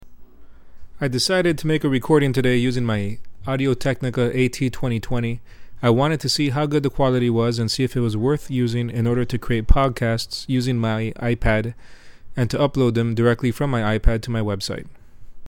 This is an audio recording of the AT2020 being used with the iPad and the iPad Camera Connection kit.
Please ignore the popping “p”, please.
Audio_iPad_AT2020.mp3